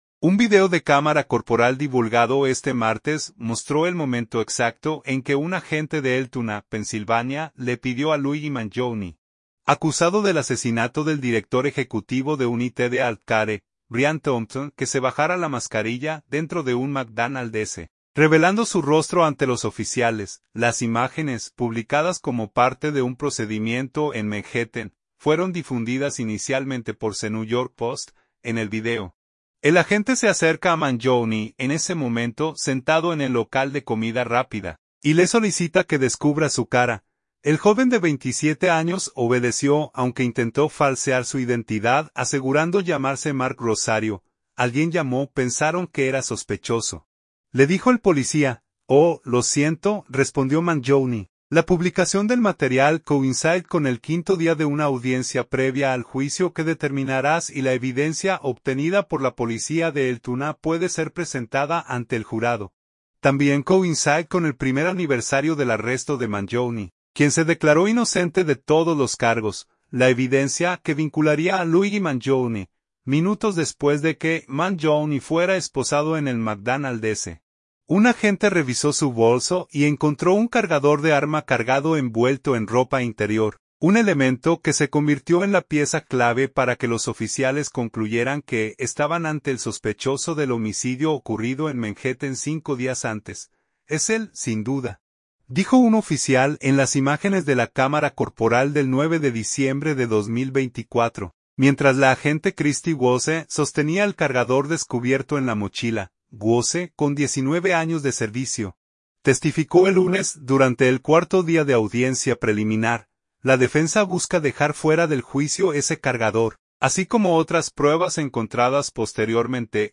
Un video de cámara corporal divulgado este martes mostró el momento exacto en que un agente de Altoona, Pensilvania, le pidió a Luigi Mangione, acusado del asesinato del director ejecutivo de UnitedHealthcare, Brian Thompson, que se bajara la mascarilla dentro de un McDonald’s, revelando su rostro ante los oficiales.